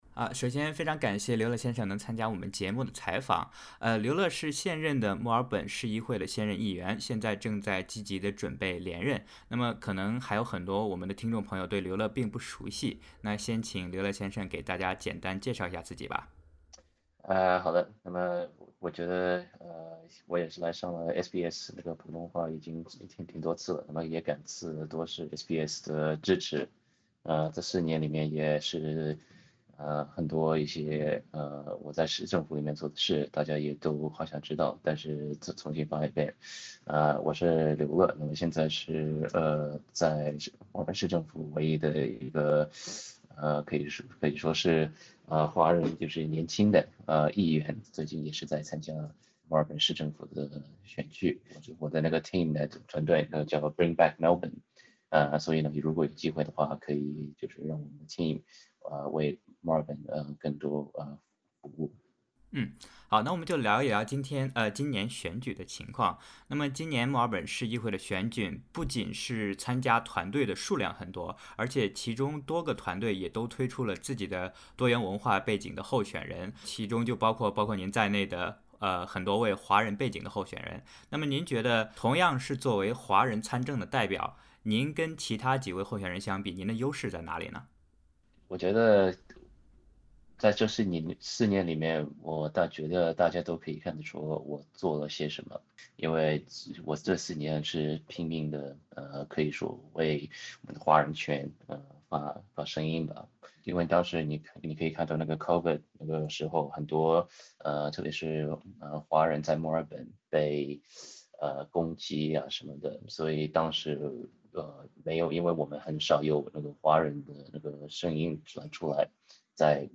点击上方音频，收听完整采访 关注更多澳洲新闻，请在Facebook上关注SBS Mandarin，或在微博上关注澳大利亚SBS广播公司。